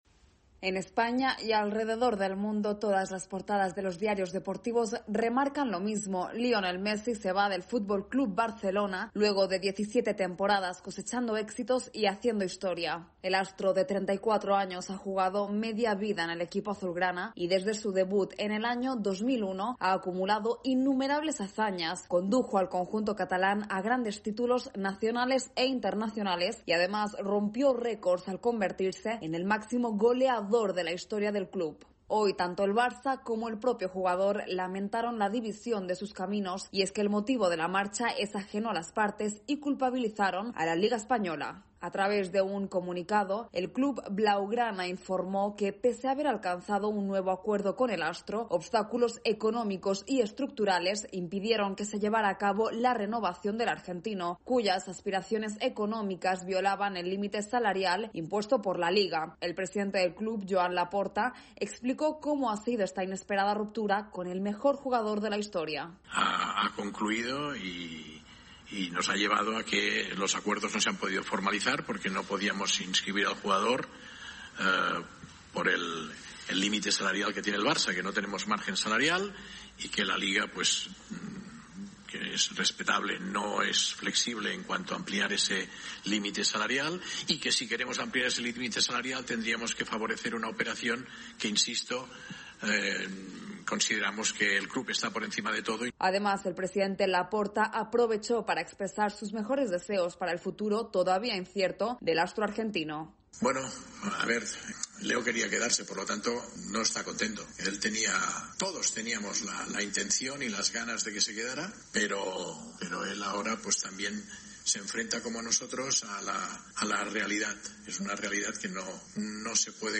desde la Voz de América en Madrid